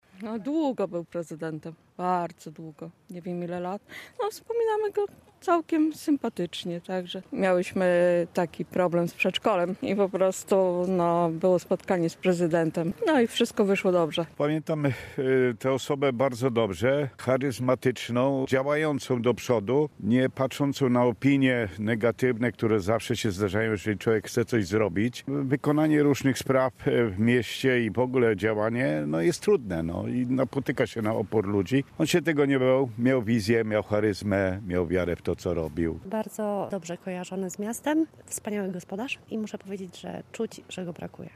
Na rzeszowskim cmentarzu Wilkowyja wiele zniczy i świec przy grobie Tadeusza Ferenca zapalają najbliżsi i mieszkańcy miasta.
Rzeszowianie, którzy zatrzymują się przy nagrobku samorządowca wspominają go na ogół pozytywnie.